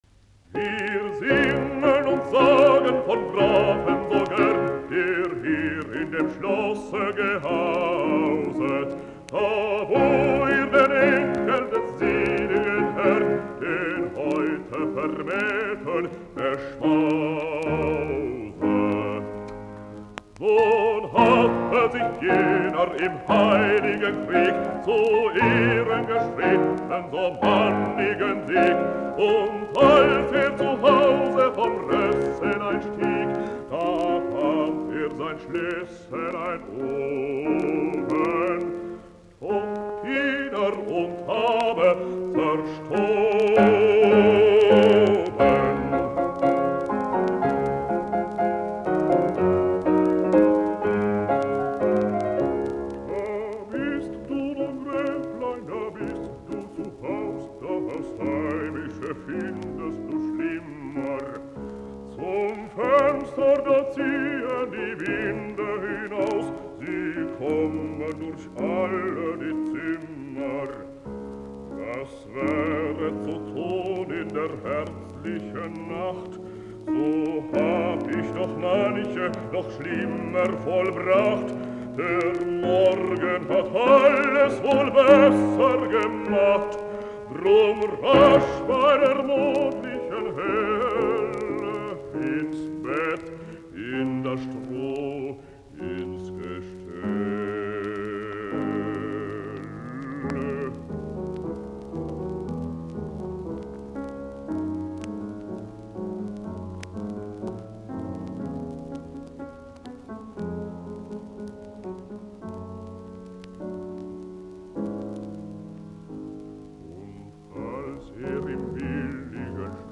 Balladit, lauluääni, piano, op20.